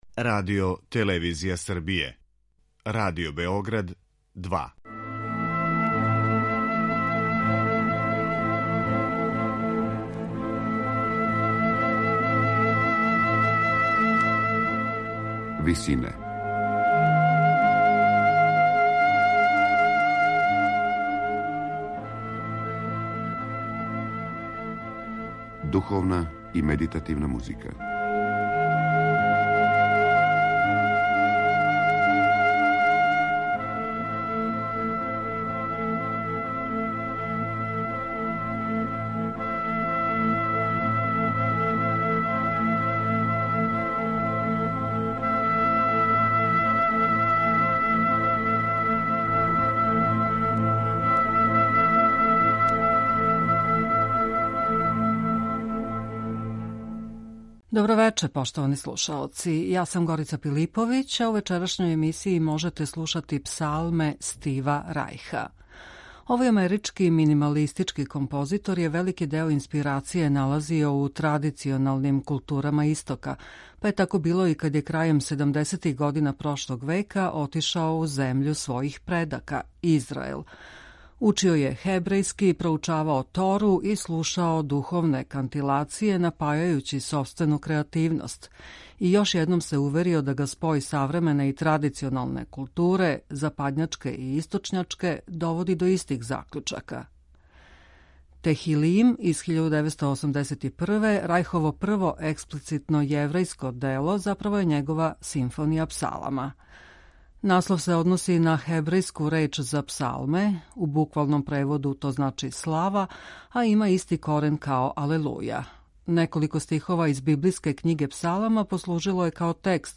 медитативне и духовне композиције
заправо је његова симфонија псалама.